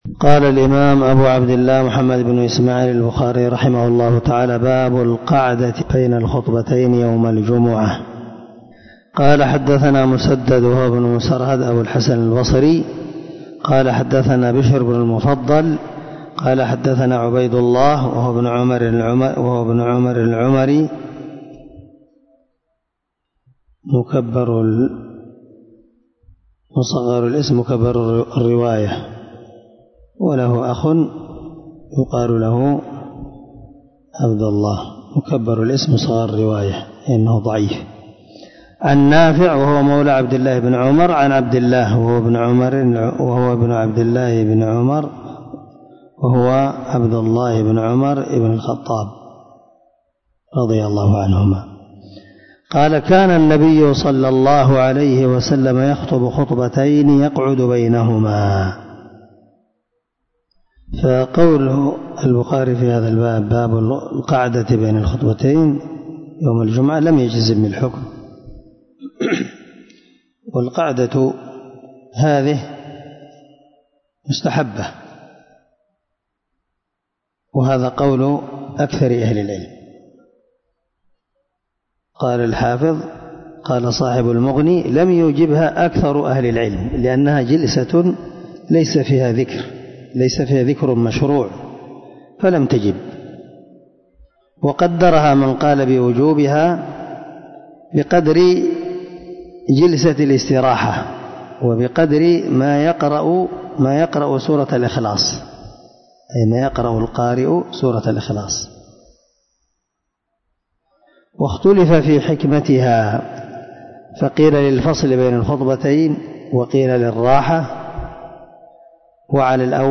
574الدرس 26من شرح كتاب الجمعة حديث رقم ( 928 ) من صحيح البخاري